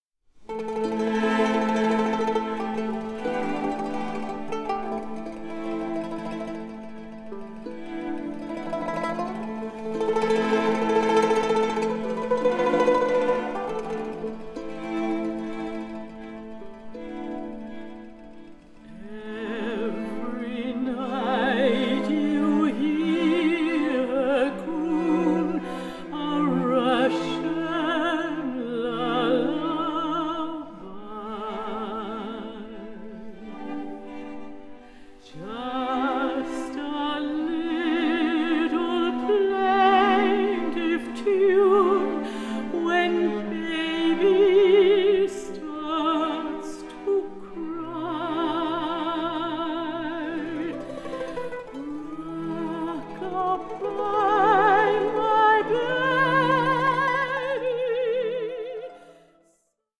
mitreißende Gesänge
begleitet von den üppigen Cellos